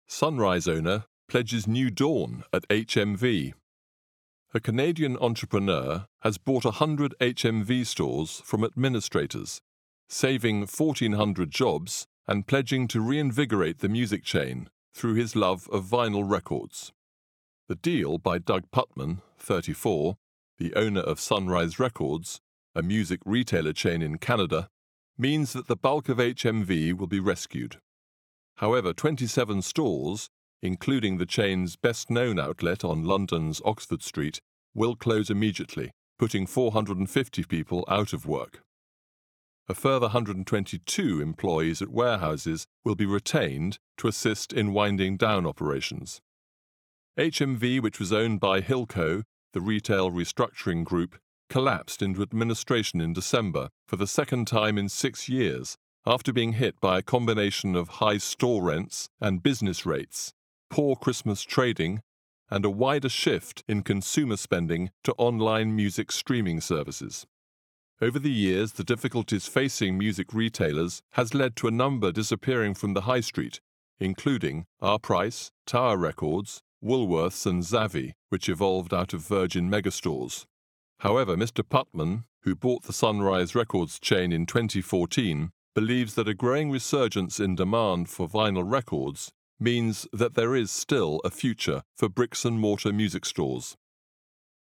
With a unique & resonant British voice...
TALKING NEWS: READING